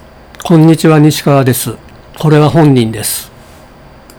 リファレンス音声